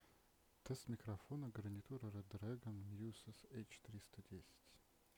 Голос передаёт более-менее натурально. Окружающие шумы подавляет неплохо.
Небольшой тест микрофона